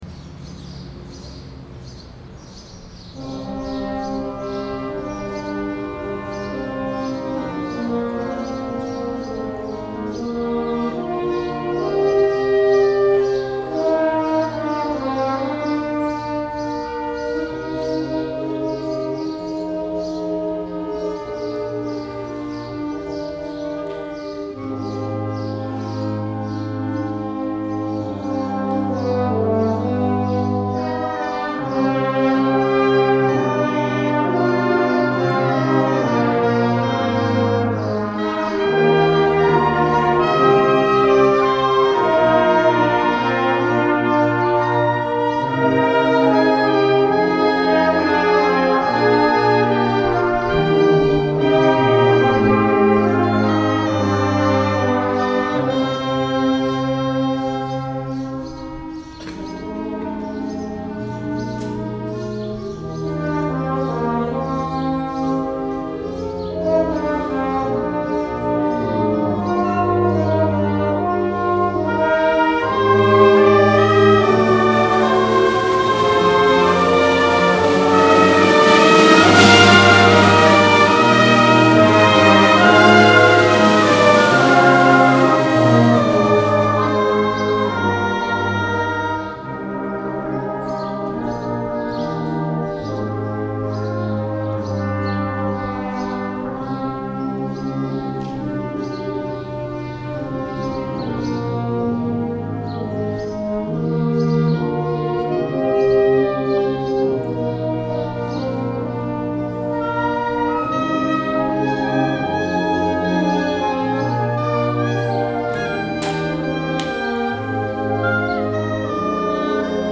Concert Band
Recorded at the Prince William County Band Festival – May 22, 2022